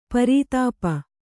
♪ parītāpa